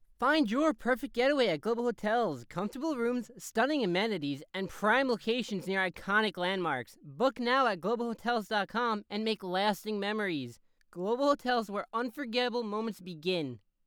hotel commercial sample
high tone. medium tone, new yorker,
Ranging from mid-high pitched tones, neardy, and nasally.
Microphone Rode NT1